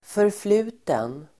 Uttal: [förfl'u:ten]